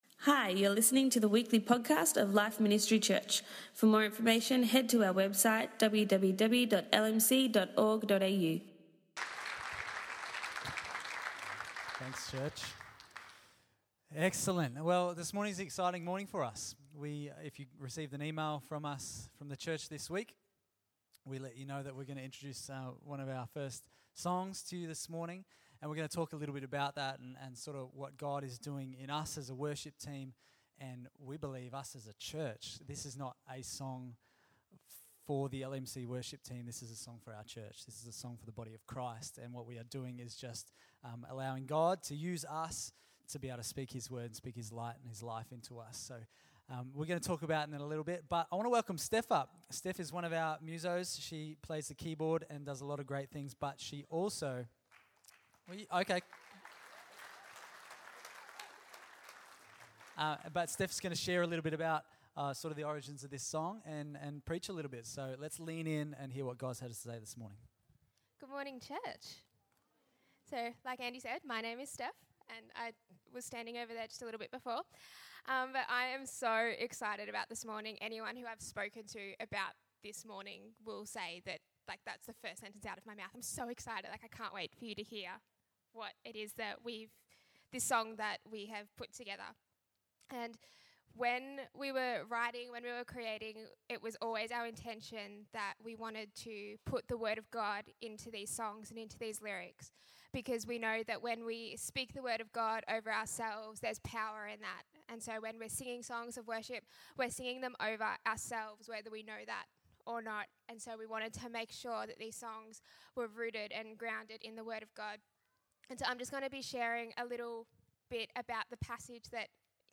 LMC Worship introduced the church to the song they wrote 'You Are Reviving Me'. It's God's breath alone that breathes life into the dead, dry areas of our life!